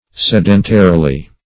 sedentarily - definition of sedentarily - synonyms, pronunciation, spelling from Free Dictionary Search Result for " sedentarily" : The Collaborative International Dictionary of English v.0.48: Sedentarily \Sed"en*ta*ri*ly\, adv.